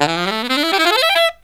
63SAXMD 07-L.wav